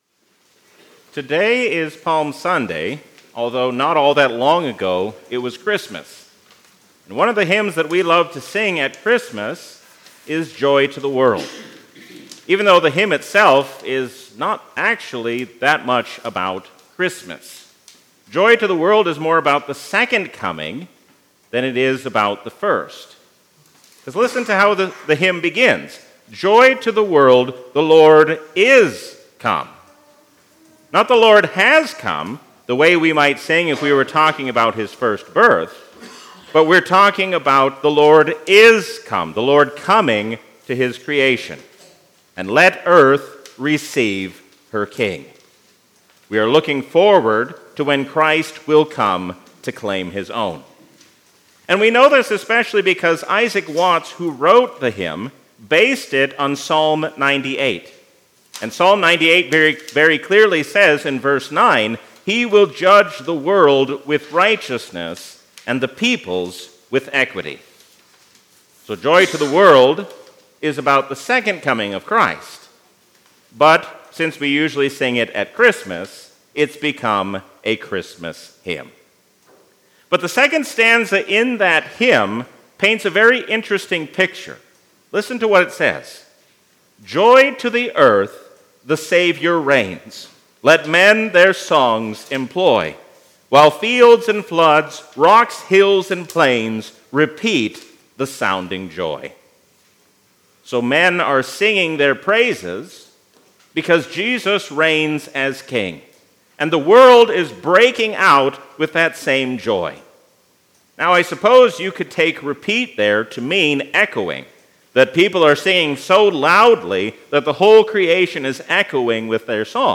A sermon from the season "Lent 2023." Jesus teaches us what it means to seek after the will of God, even as we pray for things to be taken away.